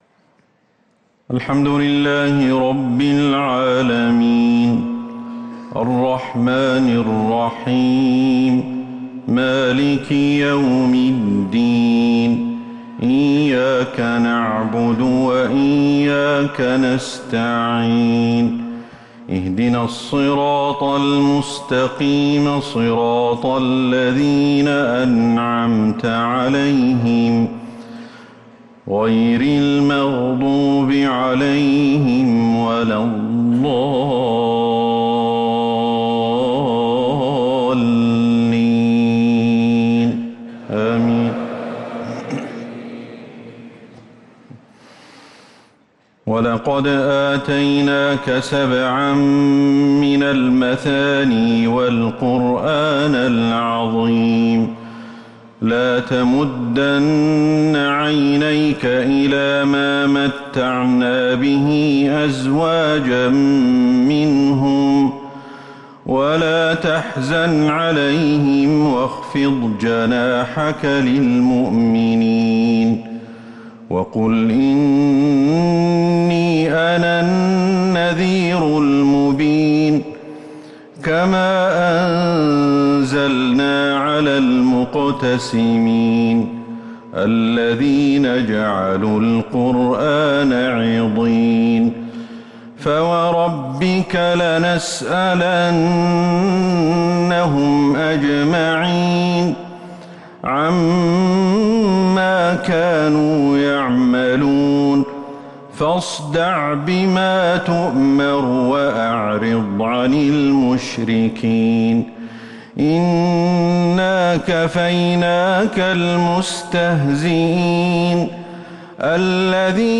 صلاة العشاء للقارئ أحمد الحذيفي 13 محرم 1445 هـ
تِلَاوَات الْحَرَمَيْن .